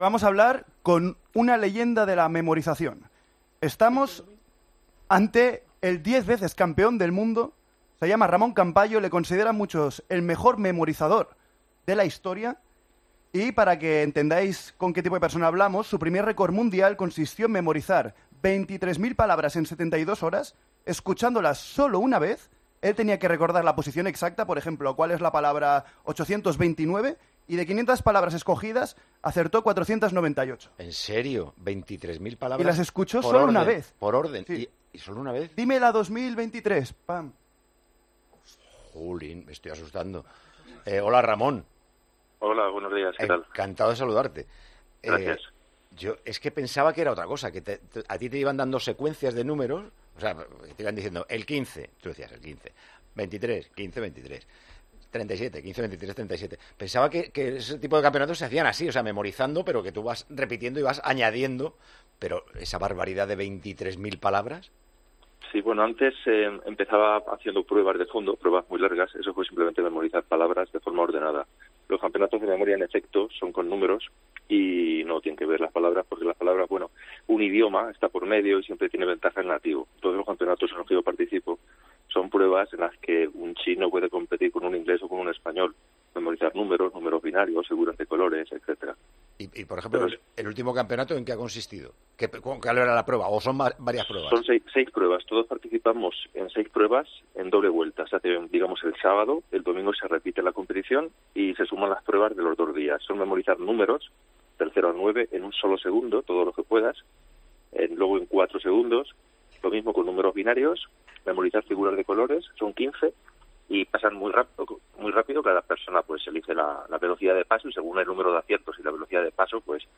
AUDIO: Hablamos con el 10 veces campeón del mundo y recordman mundial de memorización, capaz de memorizar 23.000 palabras en 72 horas escuchándolas solo una...